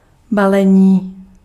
Ääntäminen
US : IPA : [ˈpæk.ɛdʒ]